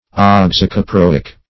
oxycaproic - definition of oxycaproic - synonyms, pronunciation, spelling from Free Dictionary Search Result for " oxycaproic" : The Collaborative International Dictionary of English v.0.48: Oxycaproic \Ox`y*ca*pro"ic\, a. (Chem.)
oxycaproic.mp3